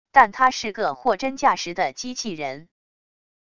但它是个货真价实的机器人wav音频